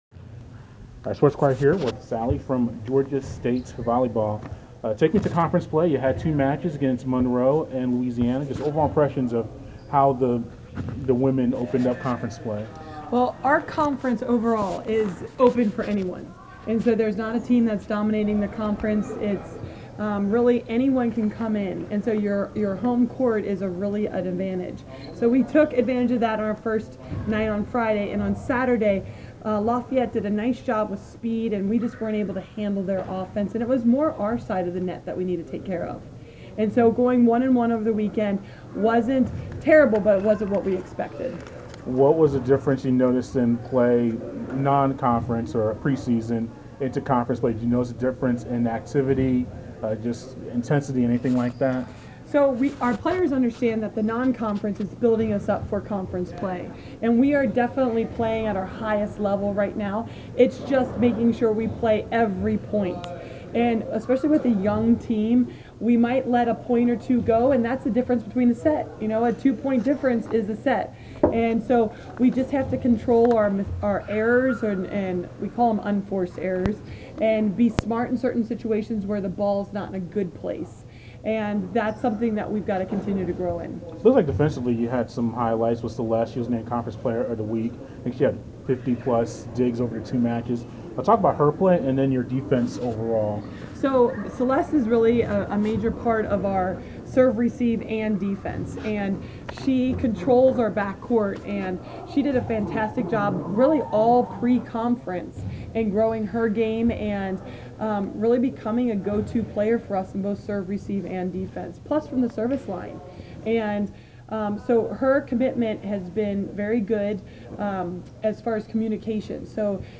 Georgia State volleyball